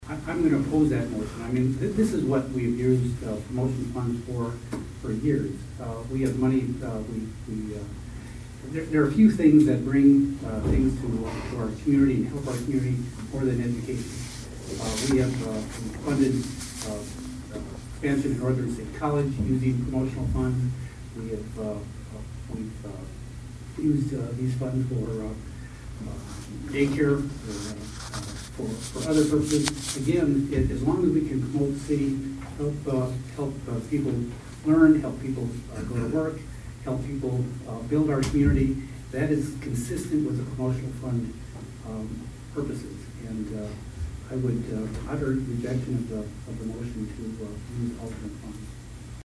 Councilman Rob Ronayne opposed the motion.